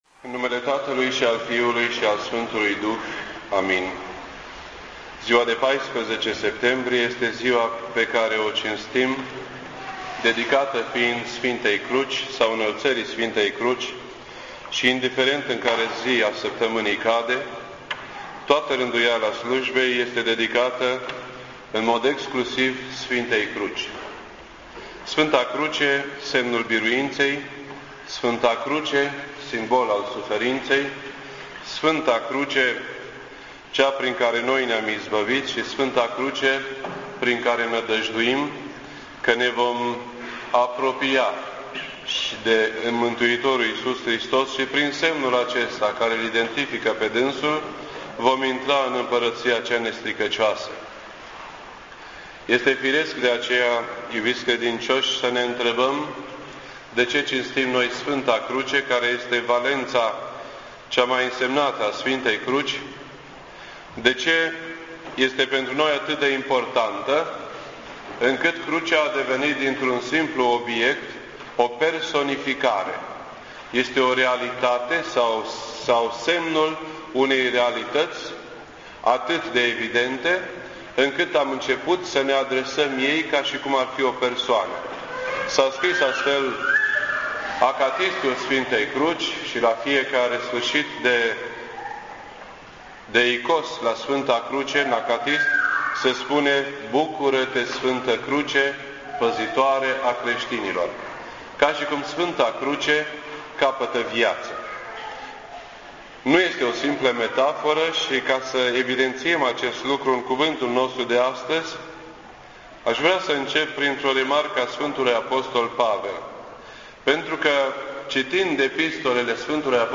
This entry was posted on Sunday, September 14th, 2008 at 9:28 AM and is filed under Predici ortodoxe in format audio.